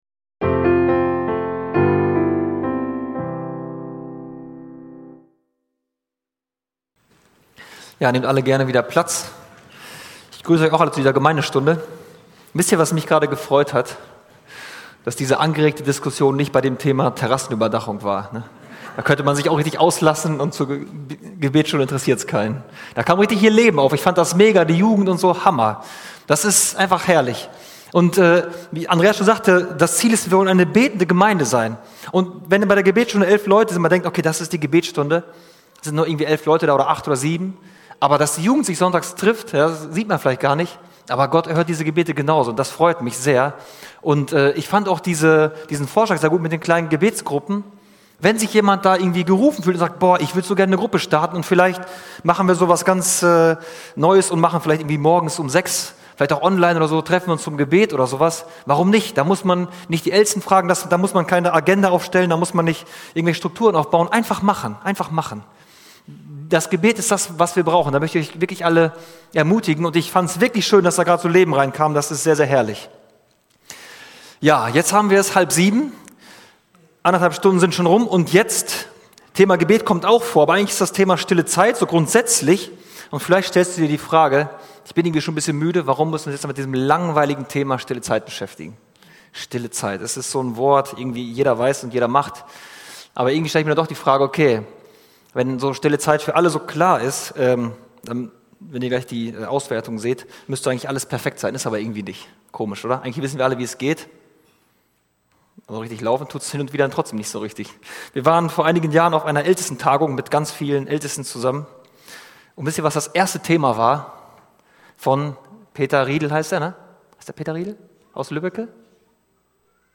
Hauptpredigt